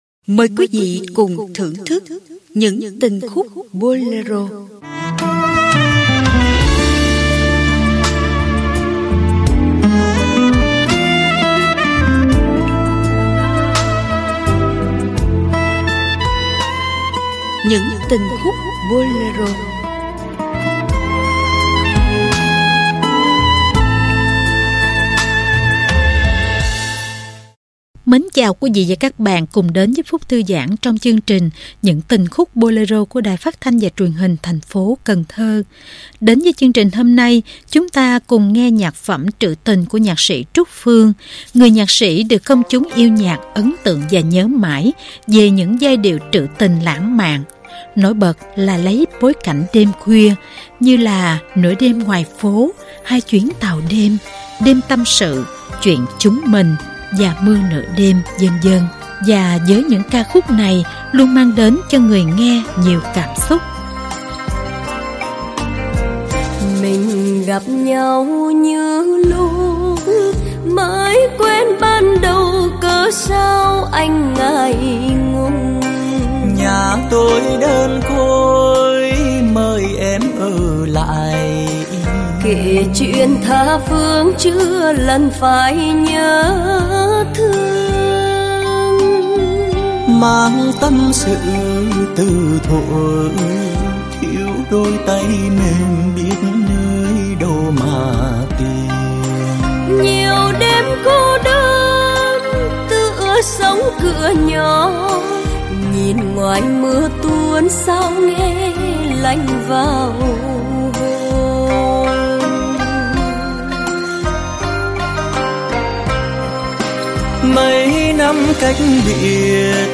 Ca nhạc Radio Tình khúc Bolero